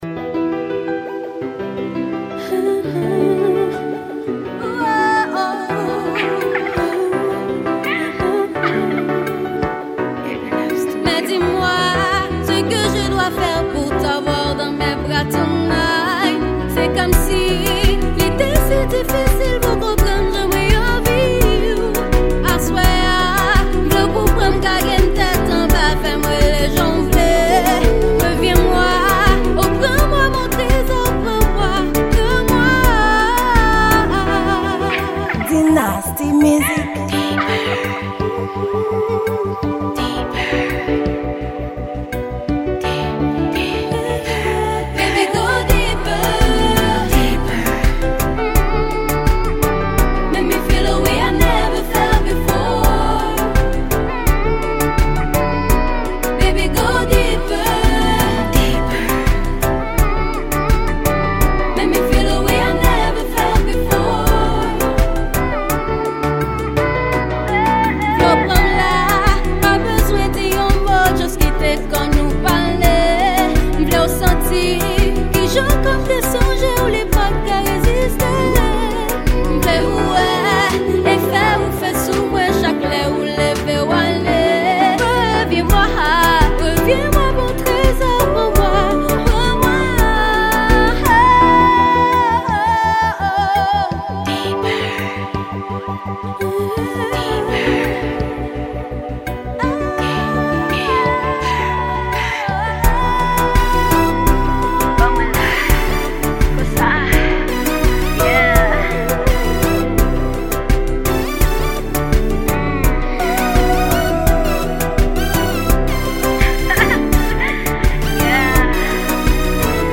Genre: Zouk.